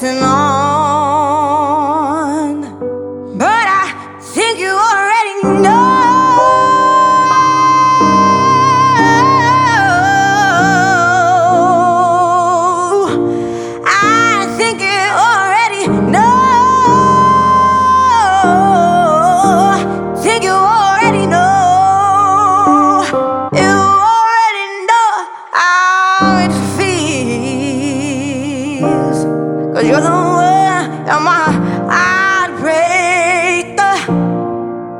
Жанр: Соул / R&b